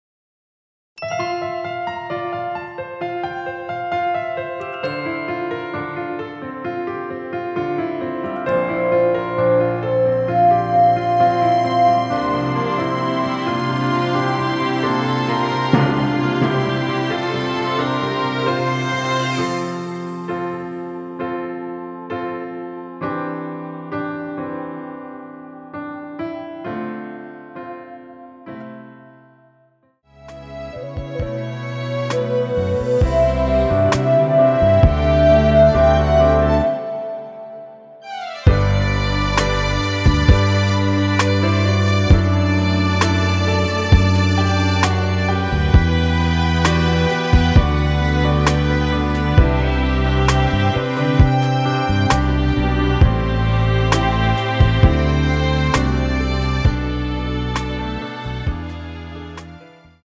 엔딩이 페이드 아웃이라 라이브 하시기 좋게 엔딩을 만들어 놓았습니다.
앞부분30초, 뒷부분30초씩 편집해서 올려 드리고 있습니다.
중간에 음이 끈어지고 다시 나오는 이유는